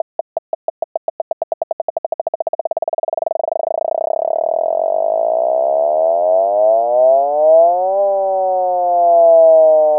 Designed in the first place for rich vocal imitation, and developed from the CHANT program, the FOF unit generator produces a complex, periodic signal with one spectral formant region.
The design on this page shows a transition from granular texture to timbre, from pulse to pitch. This is achieved by varying the fundamental frequency from 5 Hz to 200 Hz (see below).